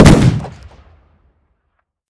shotgun-shot-2.ogg